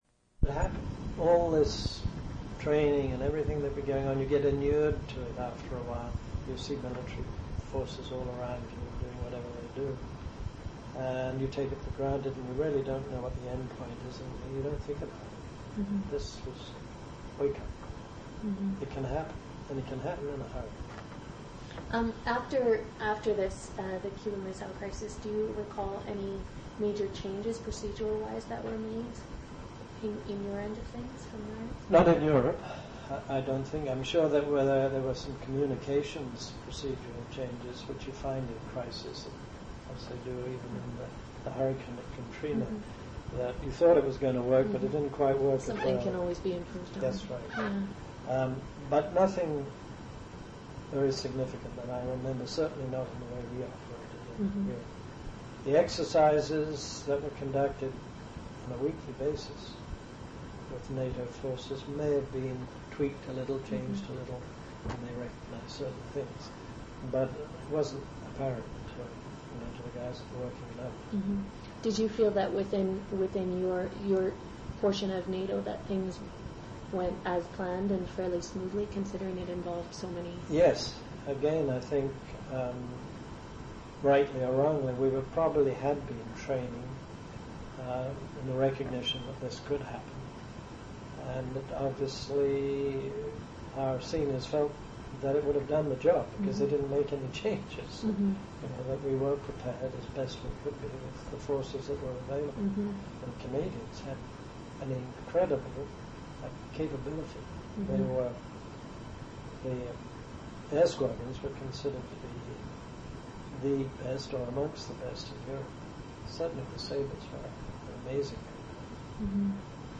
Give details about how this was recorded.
Resource type Sound Rights statement In Copyright - Educational Use Permitted Extent 2 sound recordings (MP3) Geographic Coverage France Coordinates 46, 2 Additional physical characteristics Original sound recording on audio cassette also available.